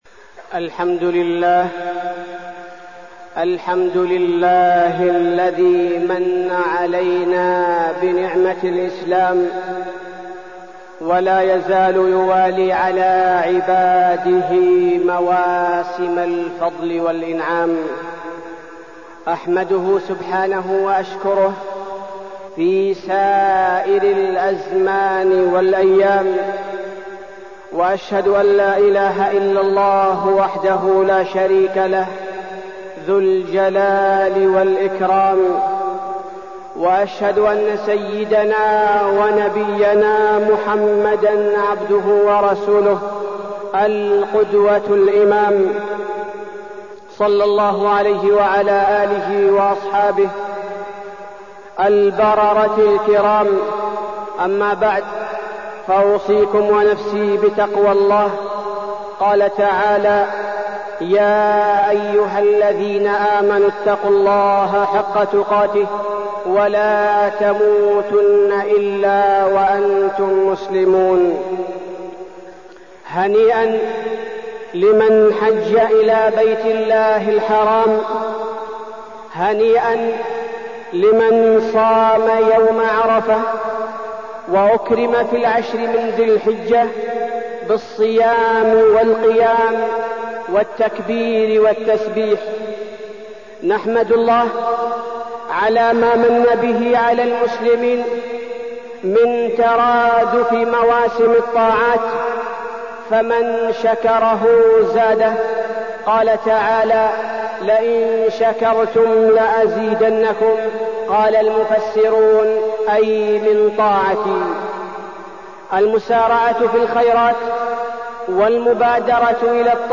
تاريخ النشر ١٦ ذو الحجة ١٤١٩ هـ المكان: المسجد النبوي الشيخ: فضيلة الشيخ عبدالباري الثبيتي فضيلة الشيخ عبدالباري الثبيتي أهل الطاعة